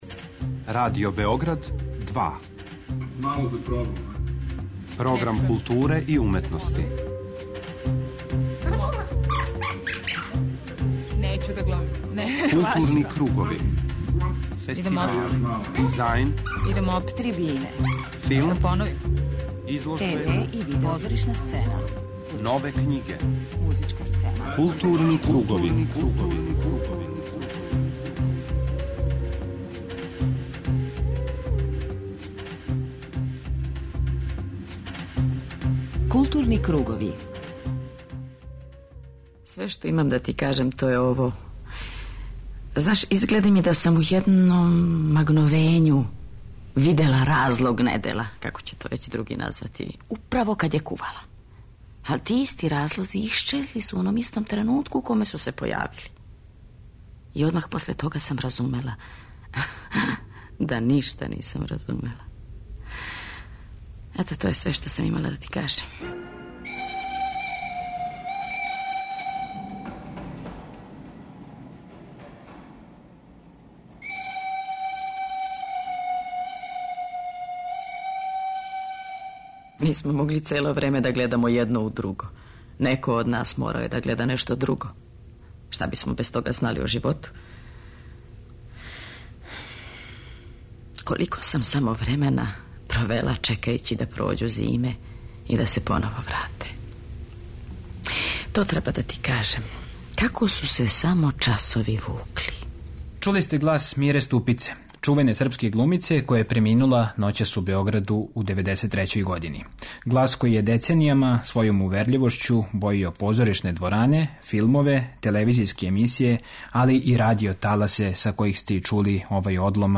У првом сату извештавамo вас о актуелним догађајима из културе, а 'Гутенбергов одговор', тематски део посвећен савременој домаћој књижевности, пратимо из Краљева.